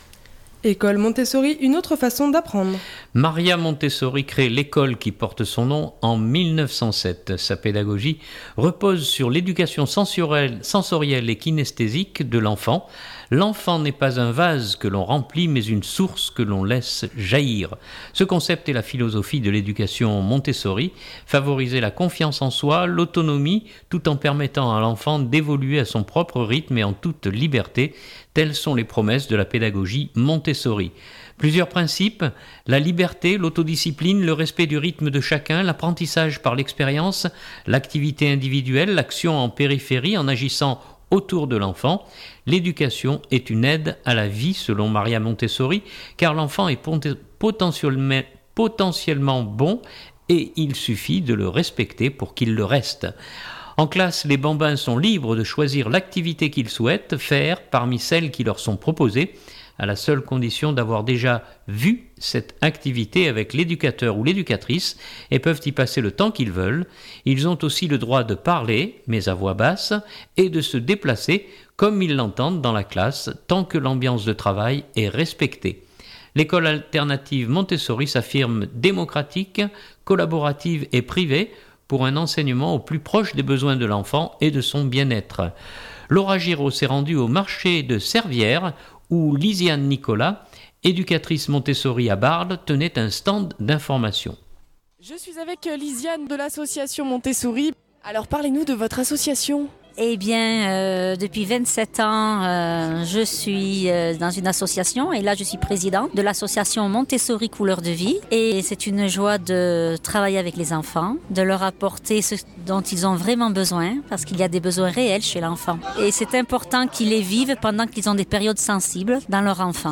au marché de Cervières